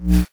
sfx_orb_absorbed.wav